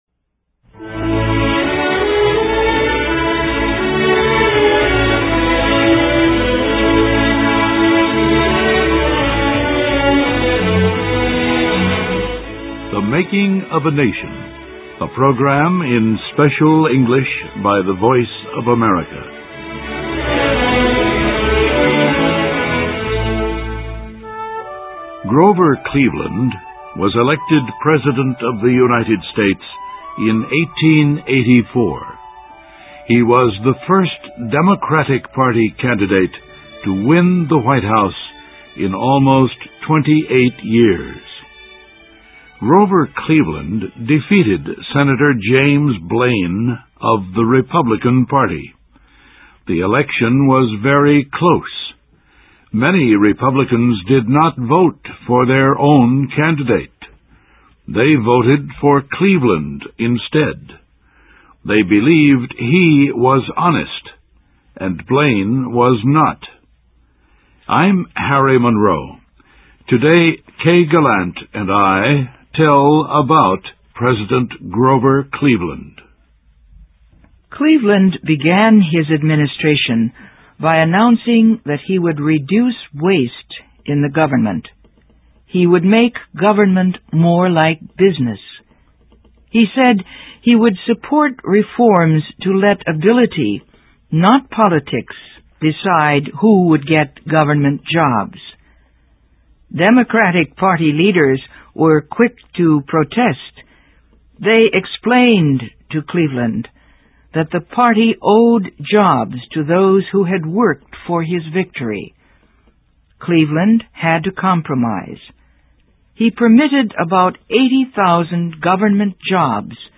THE MAKING OF A NATION – a program in Special English by the Voice of America.